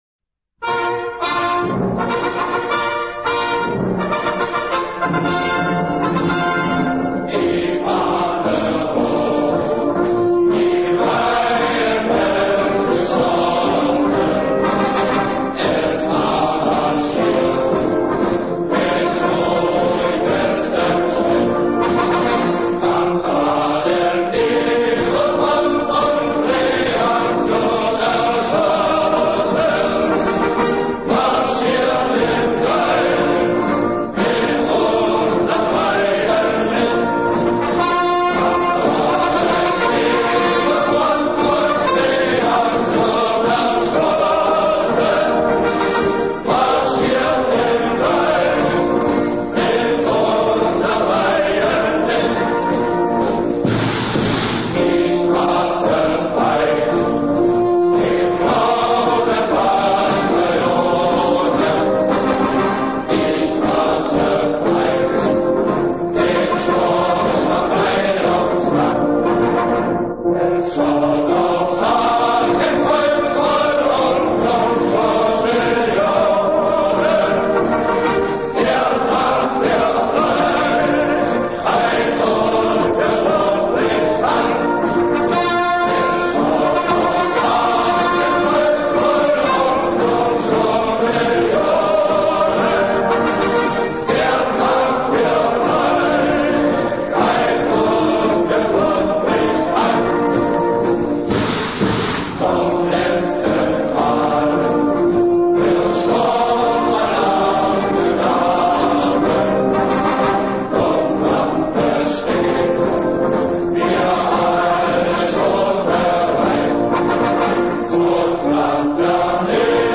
Вот еще одна из сохранившихся архивных записей (сравните с мелодией песни «Der Abenteurer»).